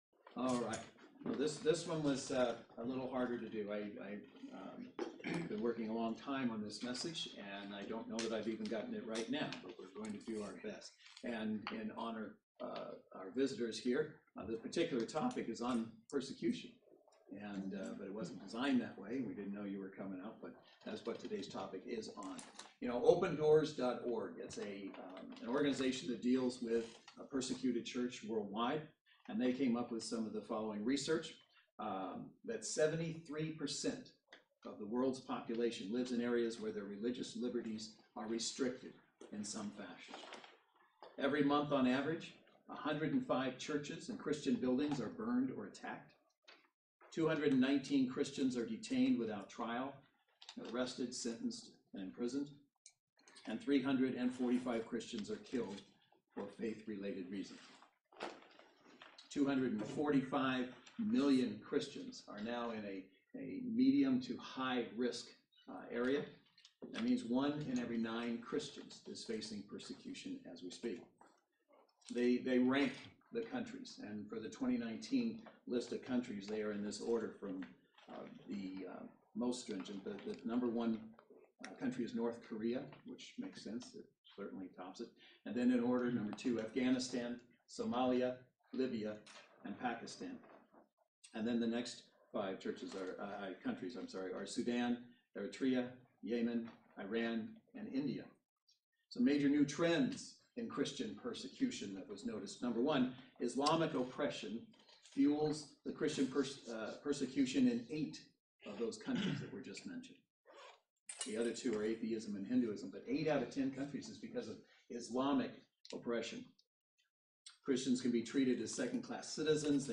Luke 21:17 Service Type: Saturday Worship Service Bible Text